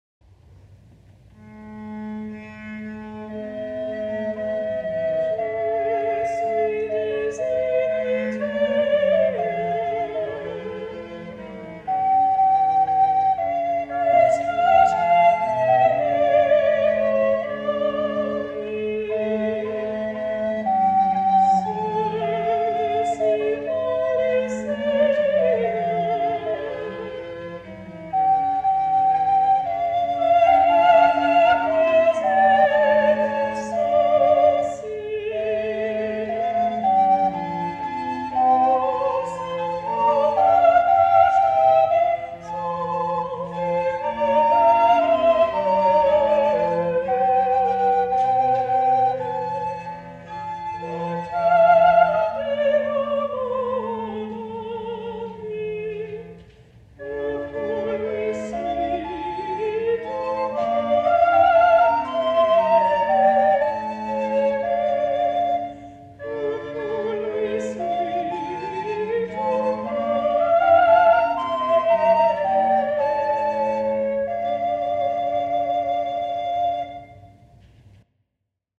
Of the several sources of this chanson two attributions are to Lupus, whose identity is also in doubt.
soprano